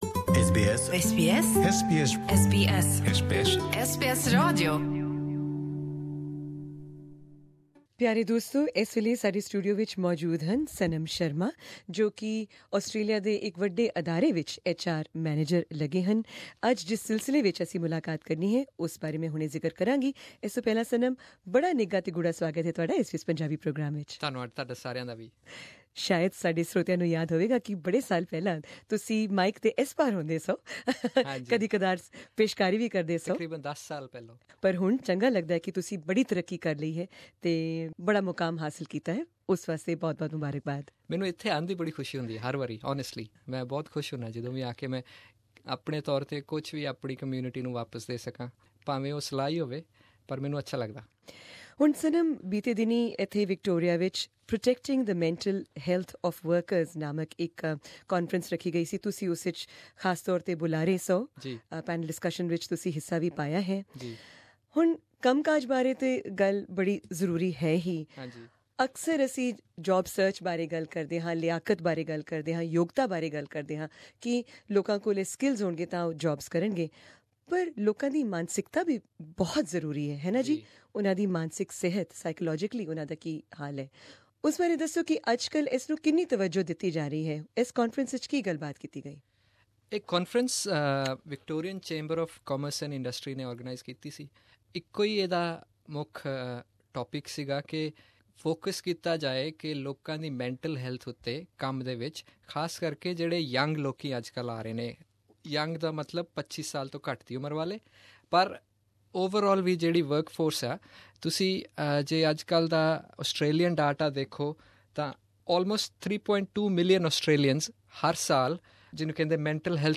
tells us more in this interview.